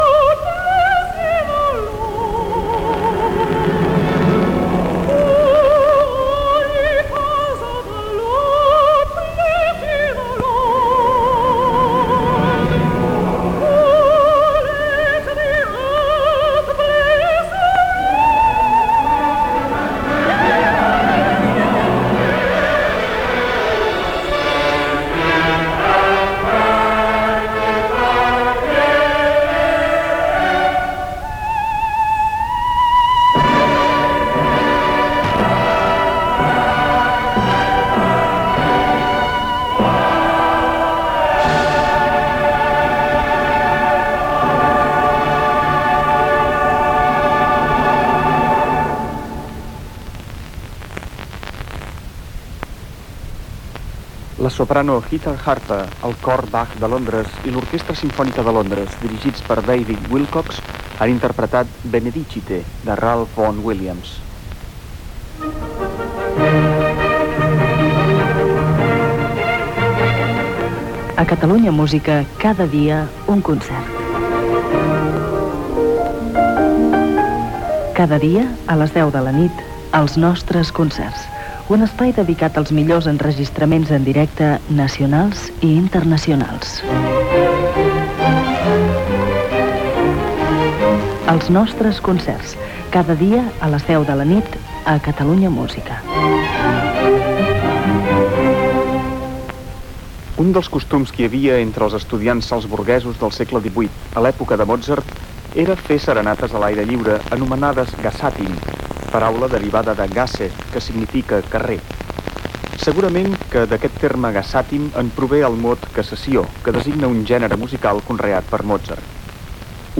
Tema musical, presentació del que s'ha escoltat, indicatiu del programa, costum dels estudiants de música al segle XVIII a Àustria i presentació d'un tema musical
Musical